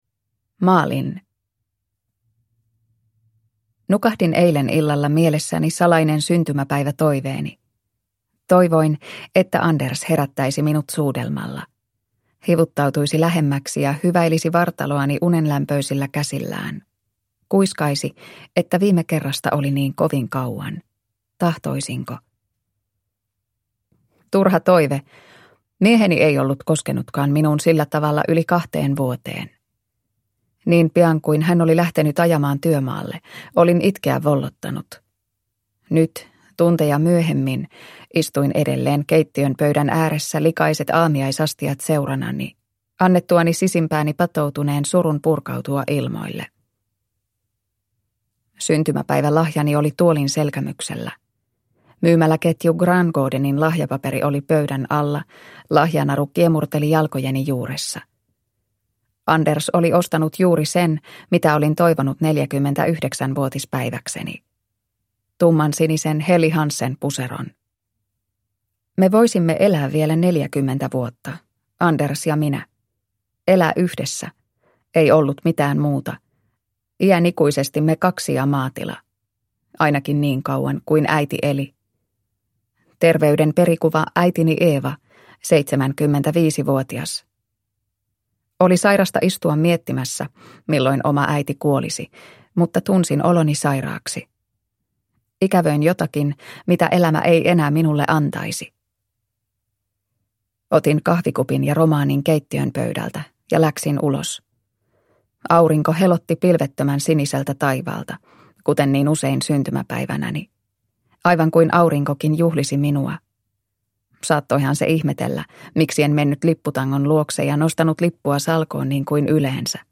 Kirjeystäviä – Ljudbok – Laddas ner